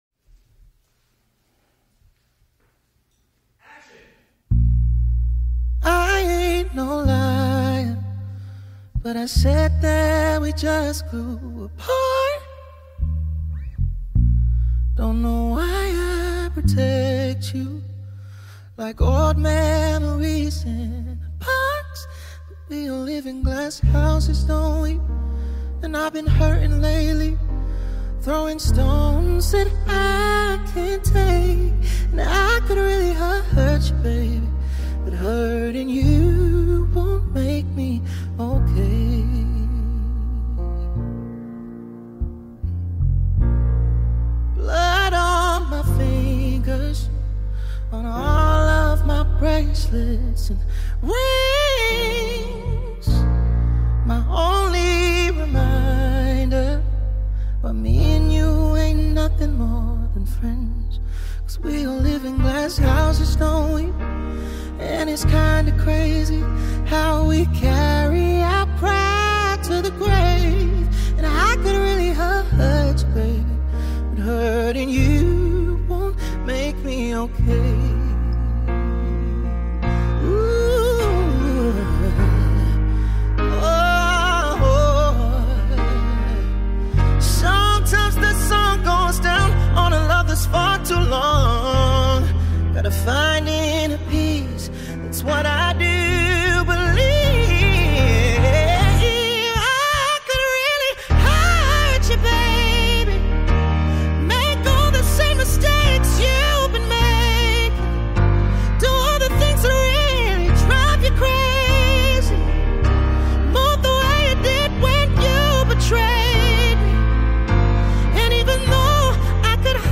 ” offering smooth vocals